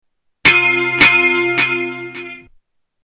Echoing guitar chord